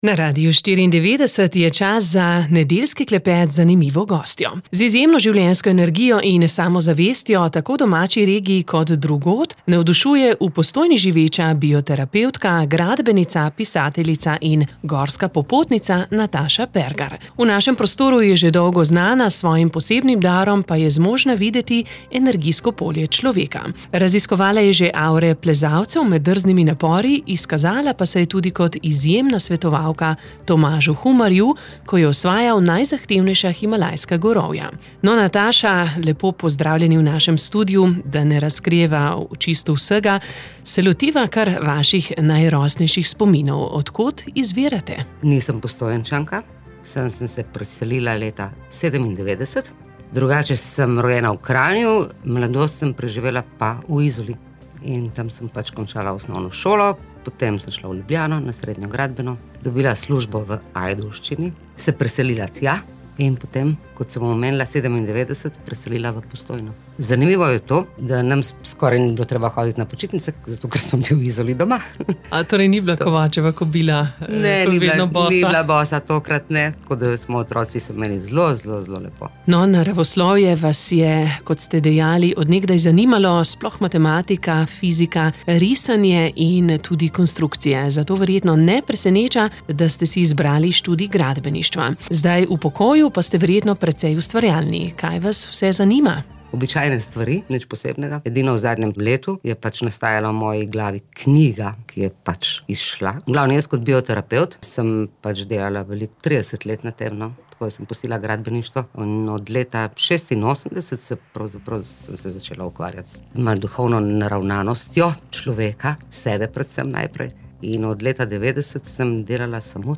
V nedeljskem klepetu nam je zaupala tudi nekaj o svojih nenavadnih izkušnjah in o svojem daru.